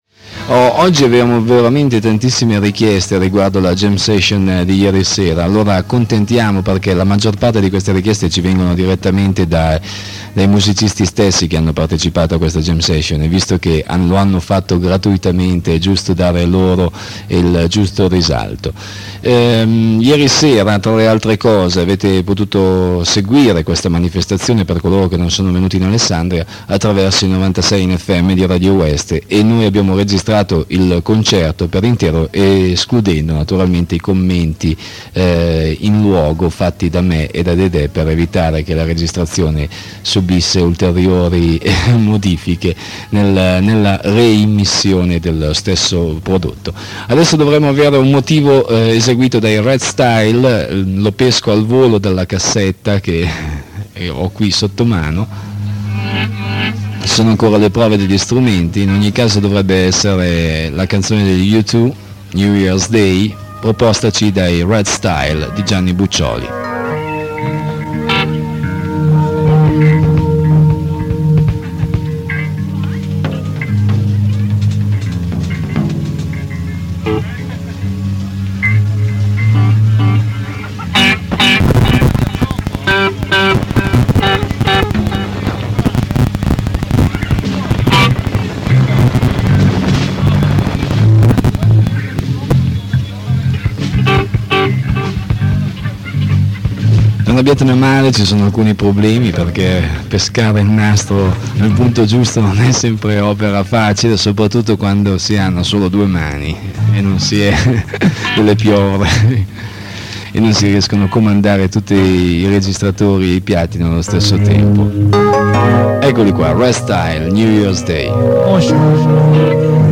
ESTRATTI DAL CONCERTO
tastiere
basso
batteria